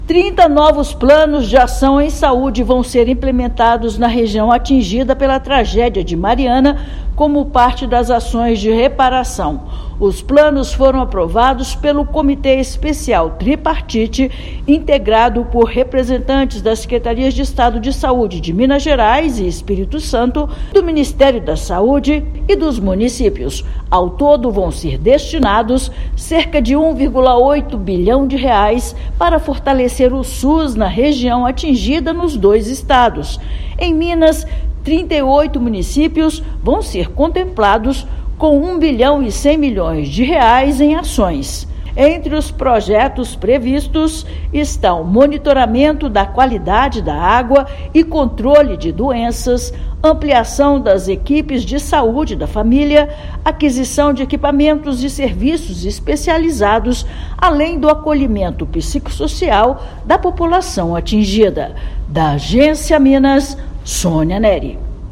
Já foram aprovados 34 Planos de Ação em Saúde, que visam melhorar estrutura, oferta e qualidade dos serviços de saúde nos municípios. Ouça matéria de rádio.